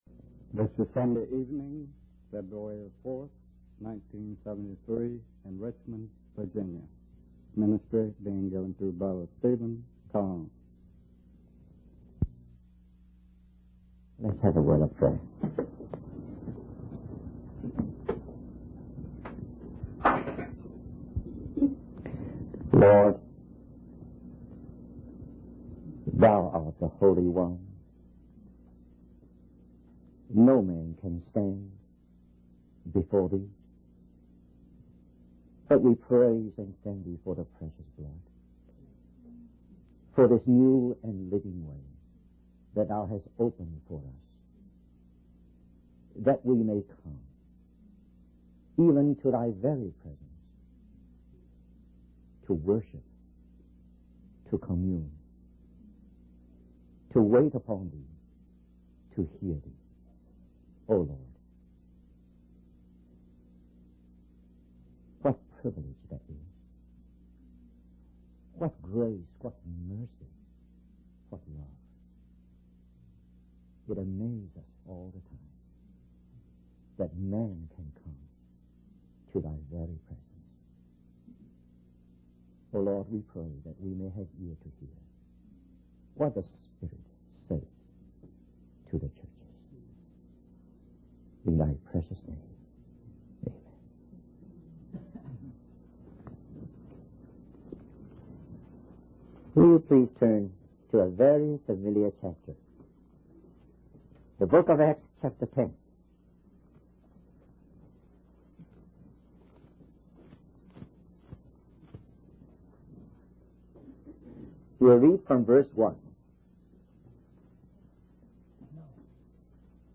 In this sermon, the speaker discusses the revelation that Peter had while praying on a rooftop.